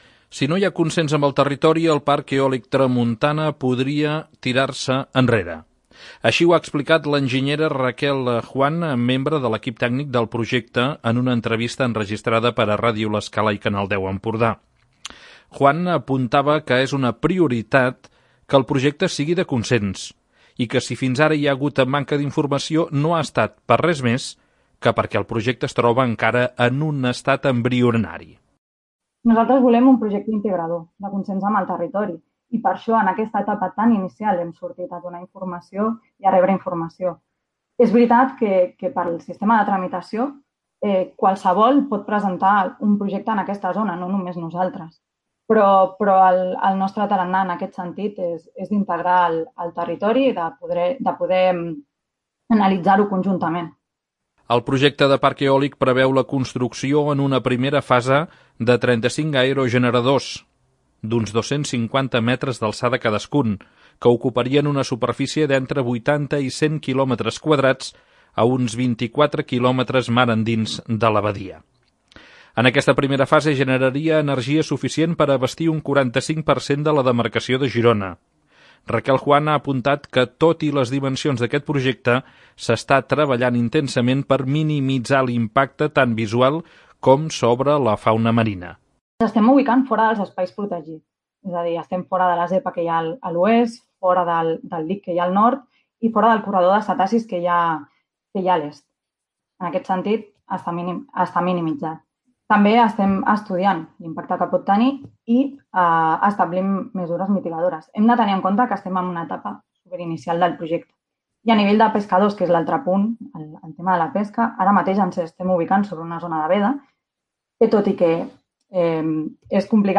Aquesta setmana ha estat el primer cop que els promotors han explicat obertament del projecte als mitjans de comunicació.